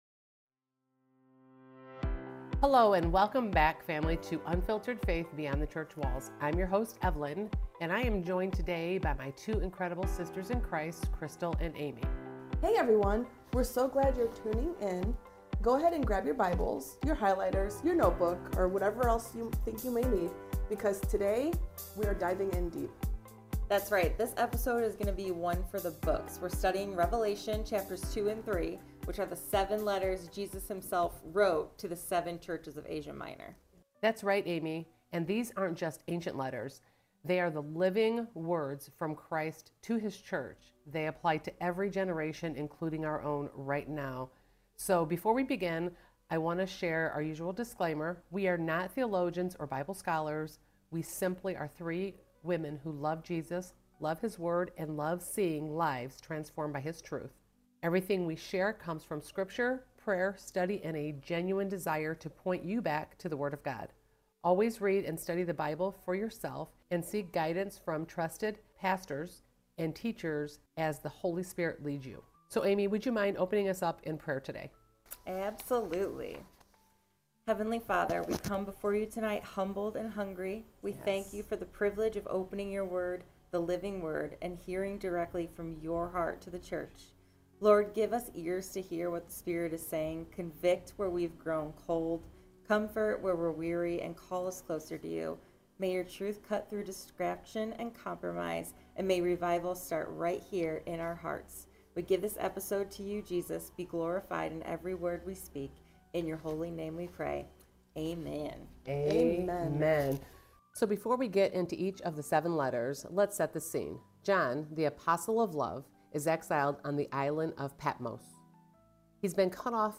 With Scripture, historical context, and commentary from teachers like John MacArthur, Warren Wiersbe, and Billy Graham, this 70-minute round-table Bible study blends conviction with encouragement.